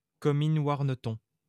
Comines-Warneton (French: [kɔmin waʁnətɔ̃]